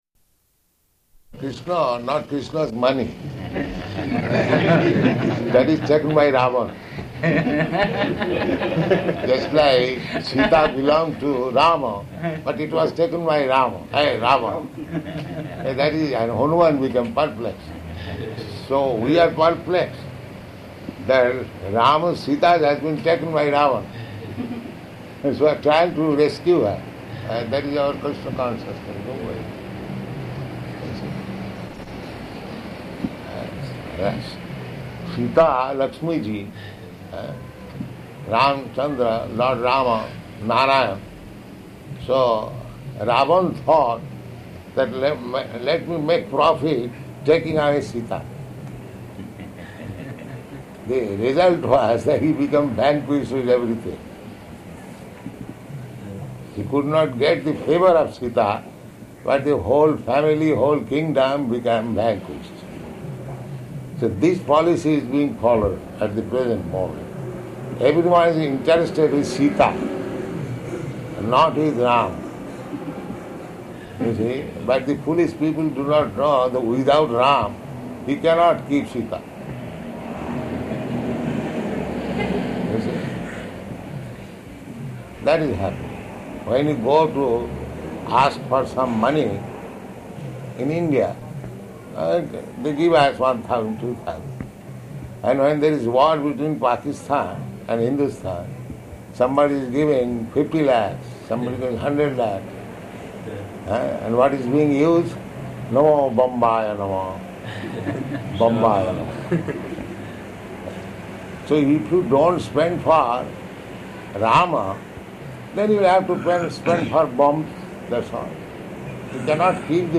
Room Conversation
Room Conversation --:-- --:-- Type: Conversation Dated: August 7th 1972 Location: London Audio file: 720807R1.LON.mp3 Prabhupāda: ...Kṛṣṇa, not Kṛṣṇa’s money.